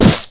whack.ogg